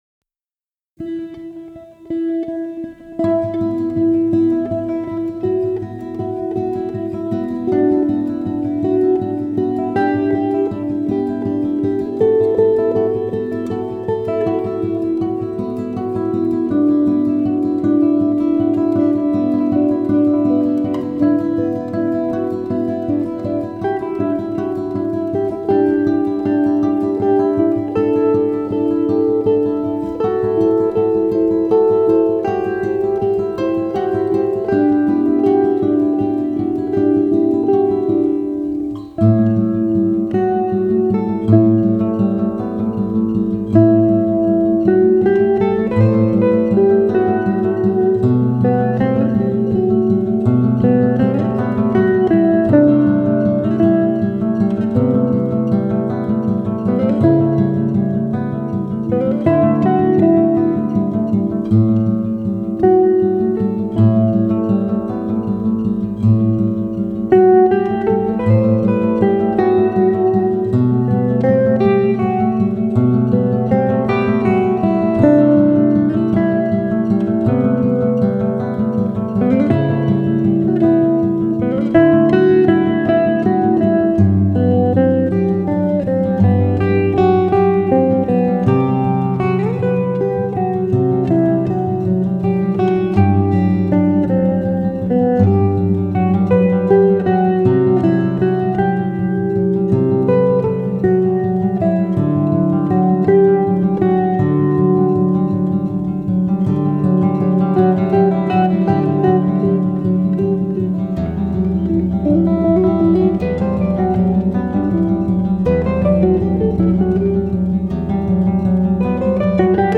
instrumentaal stuk